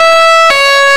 Index of /server/sound/vehicles/lwcars/sfx/sirens
uk_ambu.wav